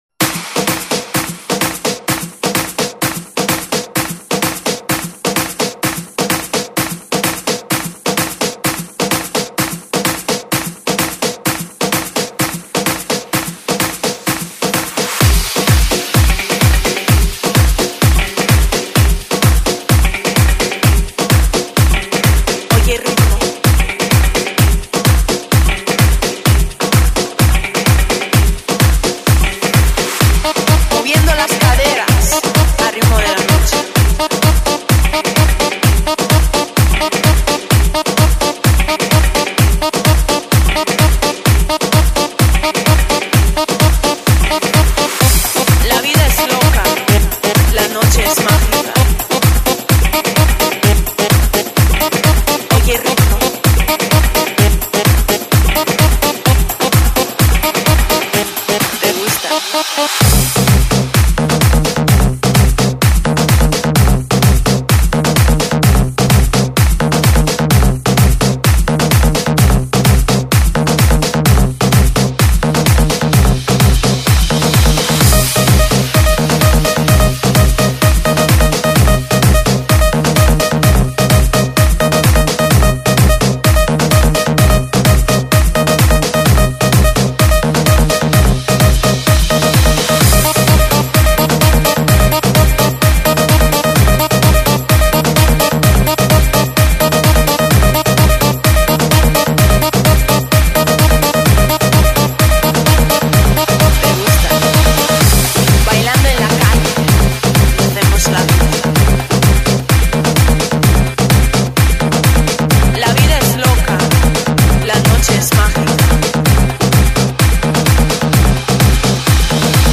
Жанр:Новогодний/Позитивный/Electro/House